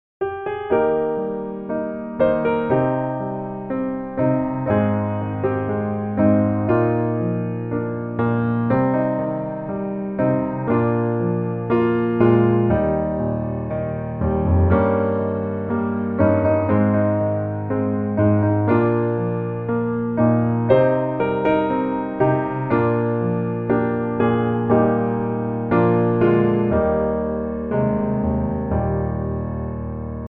Eb Major